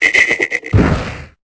Cri de Tritox dans Pokémon Épée et Bouclier.